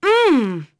Aselica-Vox-Deny.wav